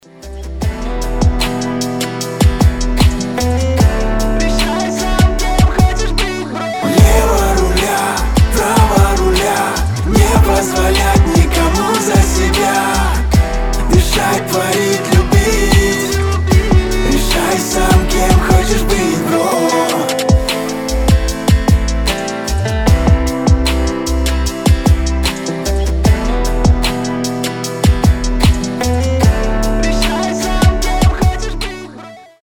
• Качество: 320, Stereo
гитара